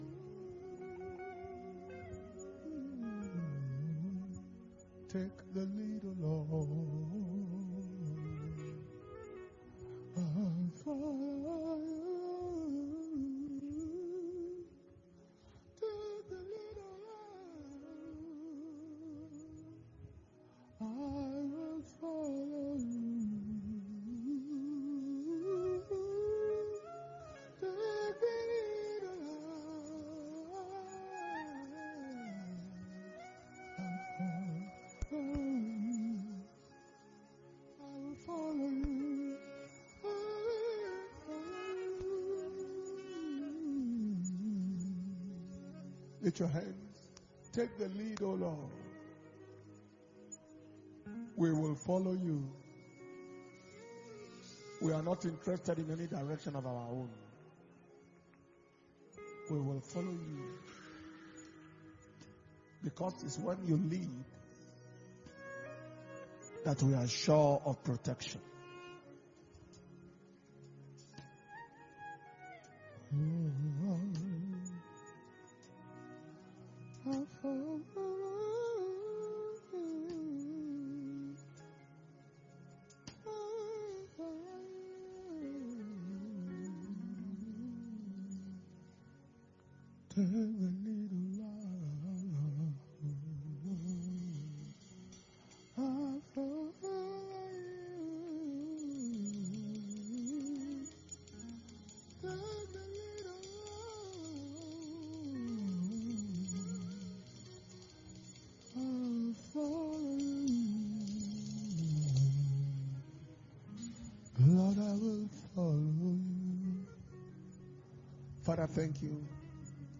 nuary 2022 Preservation And Power Communion Service – 05/01/2022